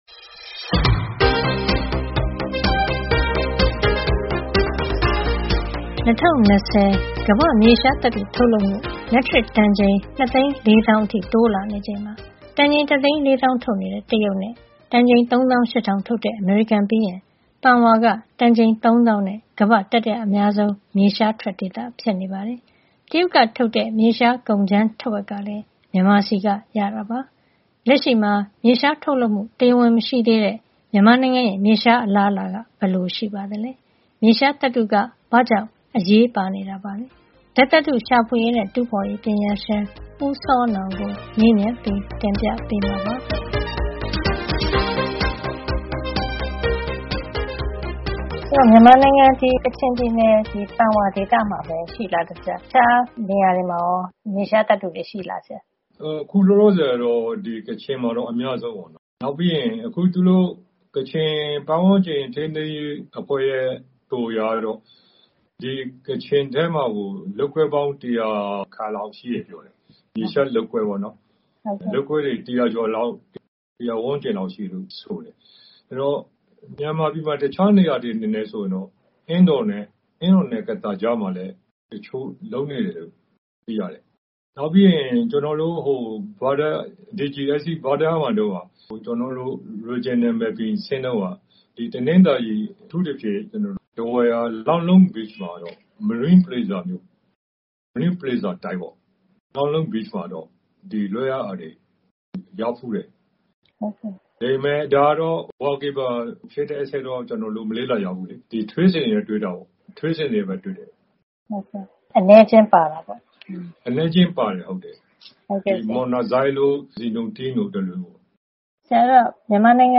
ဘူမိပညာရှင်